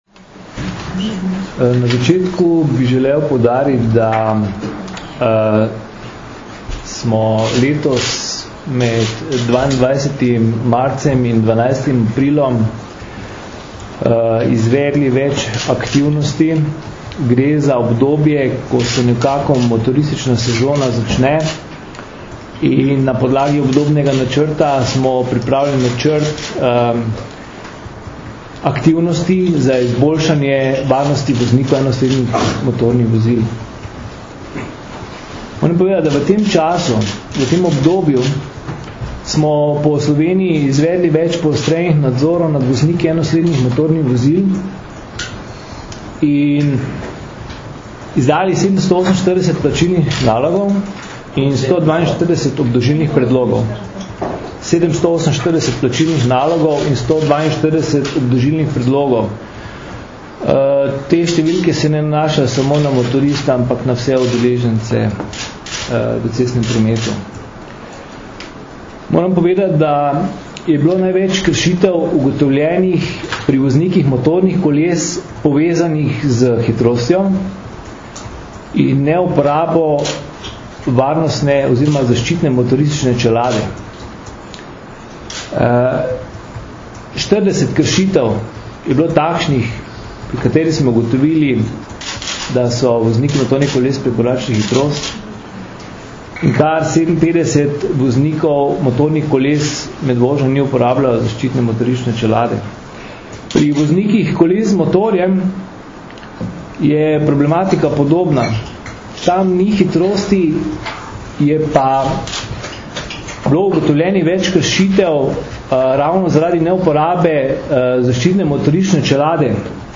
Zdravniki, policisti in motoristi skupaj za varno motoristično sezono - informacija z novinarske konference